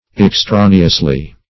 -- Ex*tra"ne*ous*ly, adv.